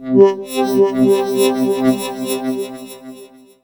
2407L SYNWOB.wav